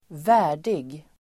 Uttal: [²v'ä:r_dig]